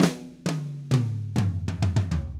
Drumset Fill 14.wav